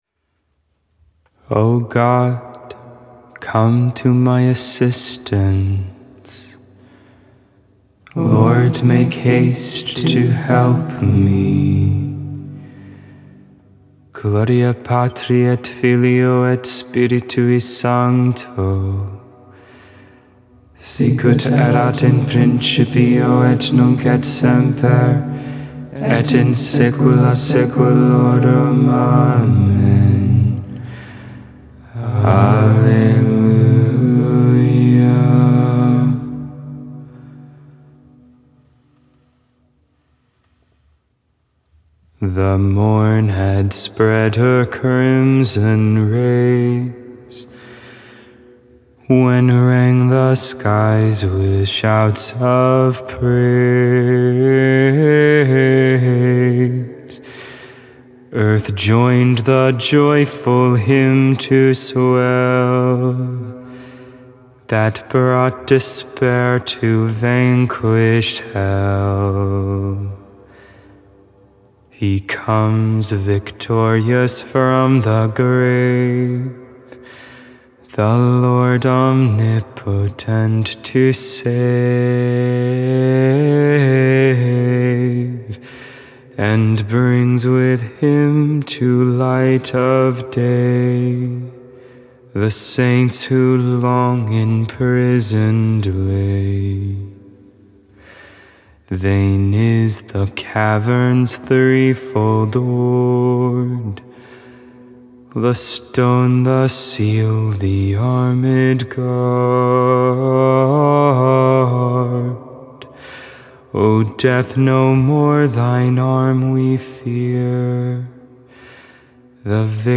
Benedictus (English, Tone 8, Luke 1v68-79)